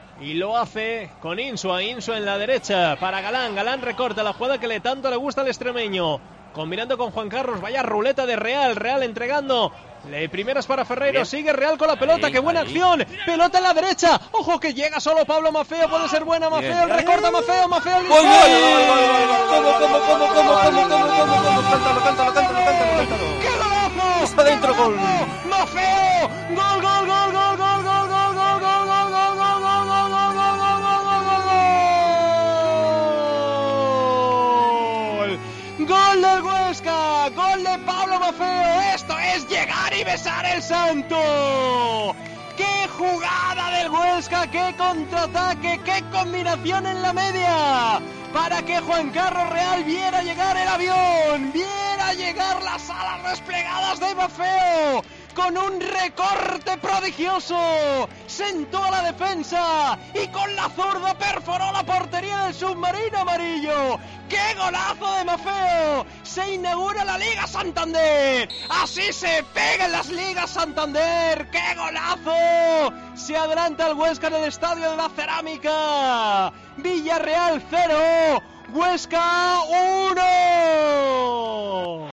Narración Gol de Maffeo / Villarreal 0-1 SD Huesca